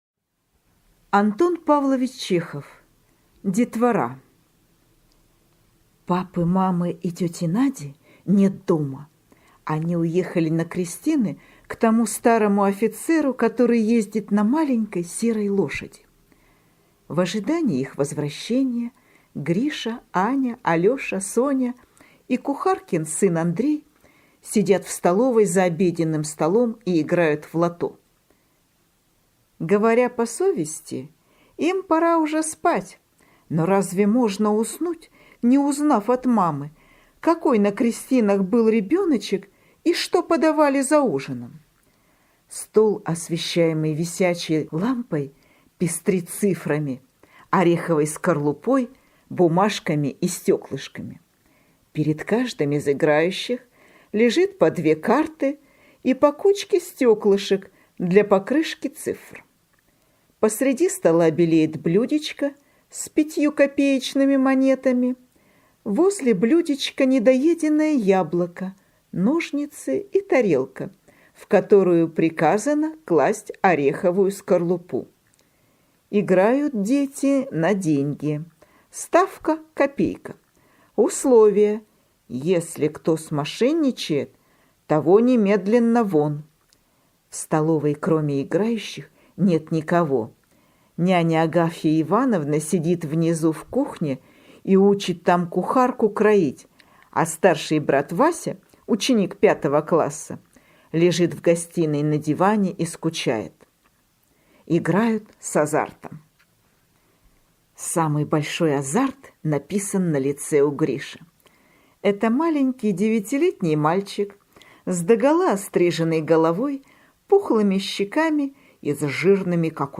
Детвора - аудио рассказ Чехова А.П. Рассказ о том, как дети поздно вечером, в ожидании родителей, играли в лото на деньги.